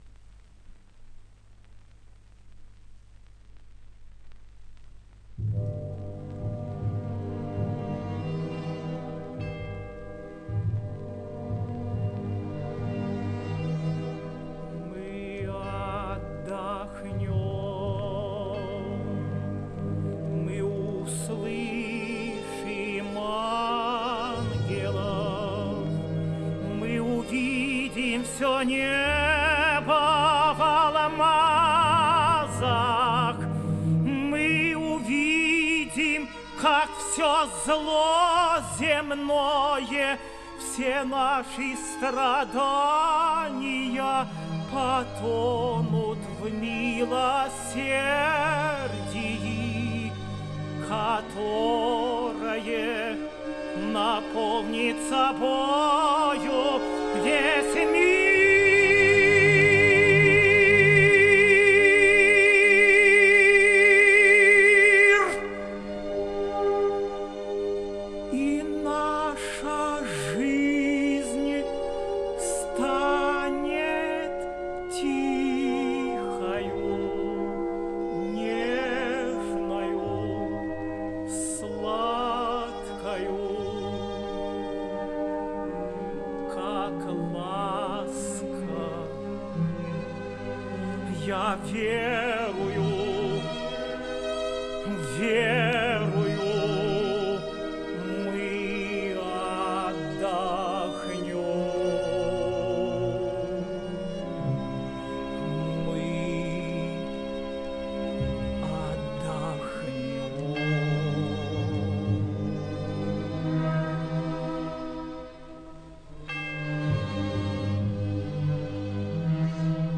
Романсы
Режим: Stereo